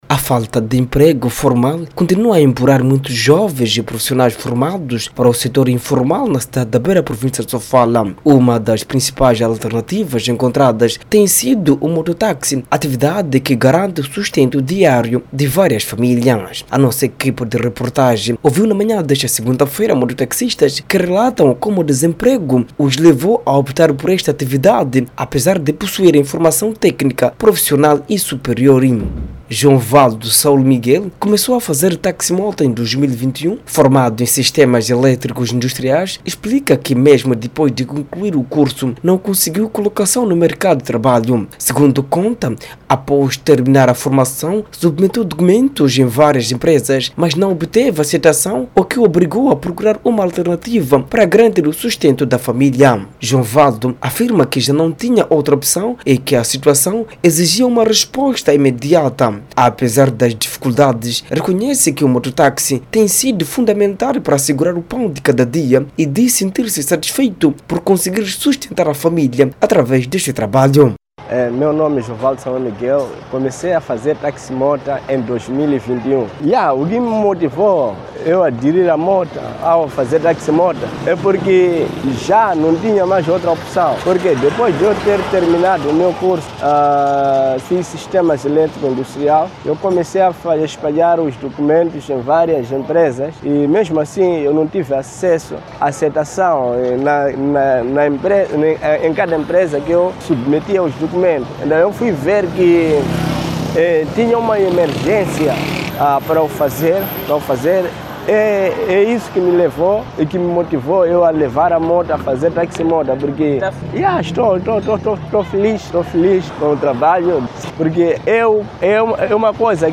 A nossa equipe de reportage ouviu na manha desta segunda-feira, mototaxistas que relataram como o desemprego os levou a optar por esta atividade, apesar de possuírem formação técnico-profissional e superior.